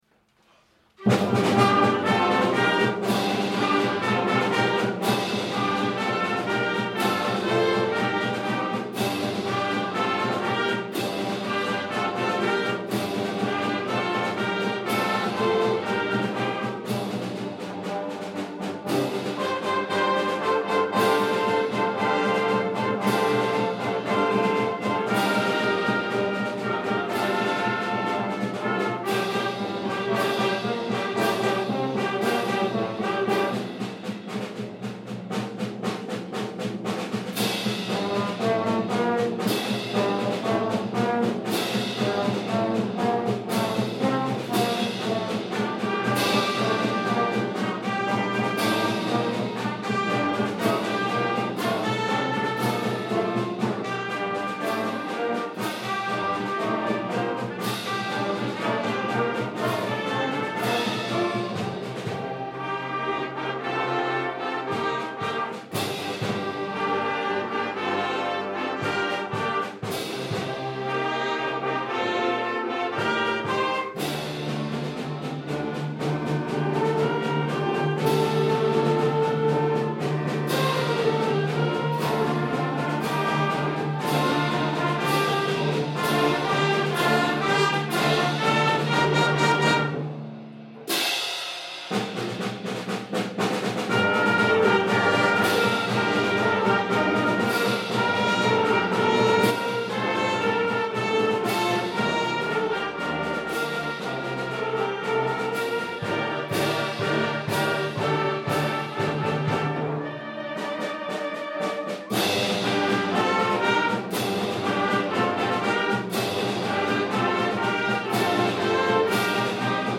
Junior Wind Band - Scorpion
Musicians in Years 7 - 9